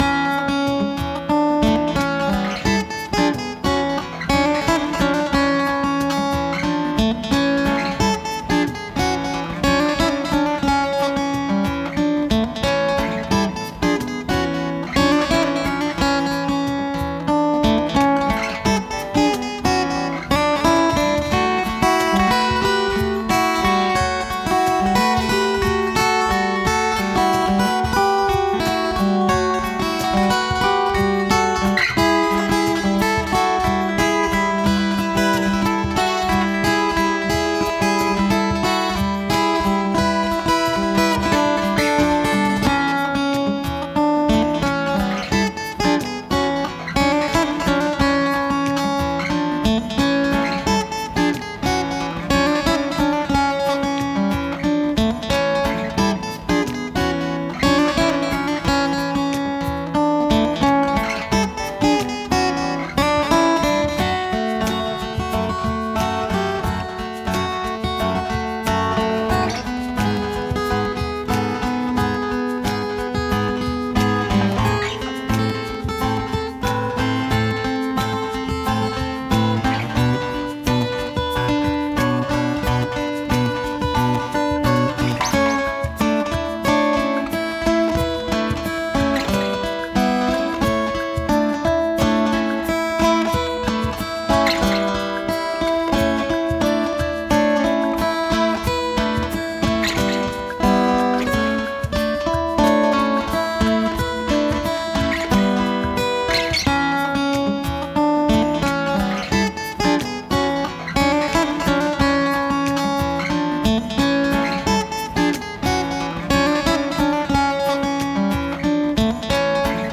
acoustic-electric guitar
Frightening Features The guitar features a spruce top, a bone nut, and a bone saddle.